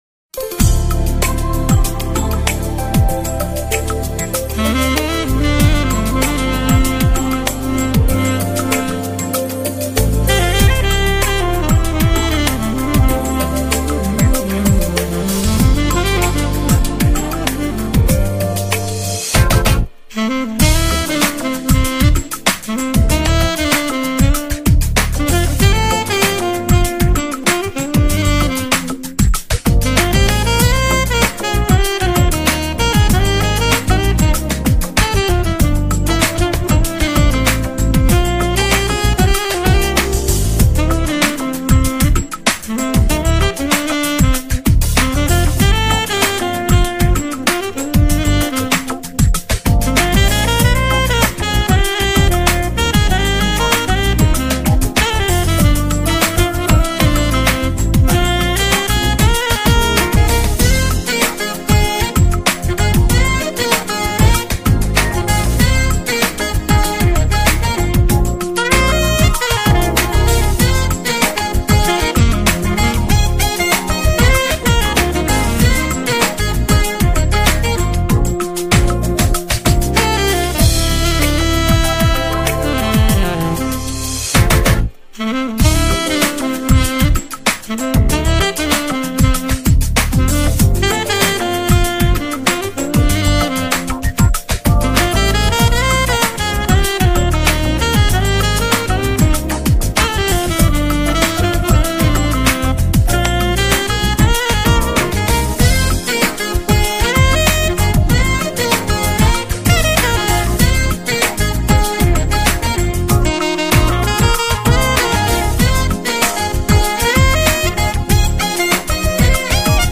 欣喜感，中音萨克斯风演奏十分之调皮活泼，演奏者随兴的神情与动作似乎就在